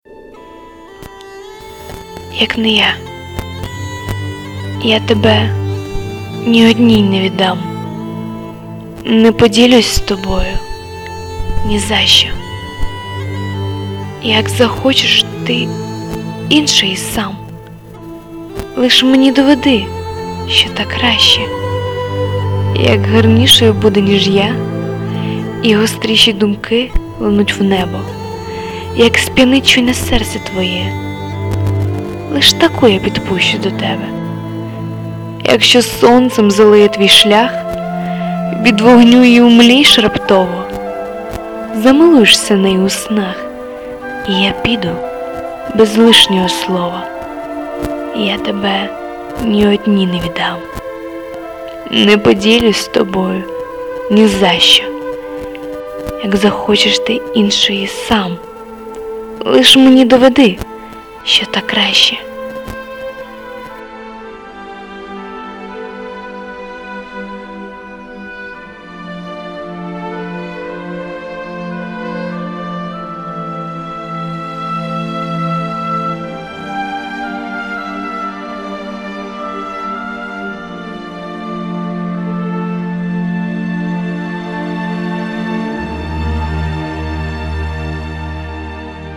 (Пробачте, за неякісний звук при озвучці, писала на швидкоруч)